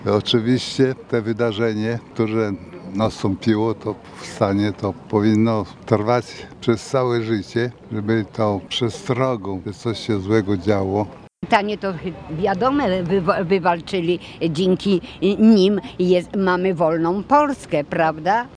Hołd powstańcom oddało też kilkudziesięciu ełczan, którzy pojawili się punktualnie o godz. 17.00 w Parku Solidarności.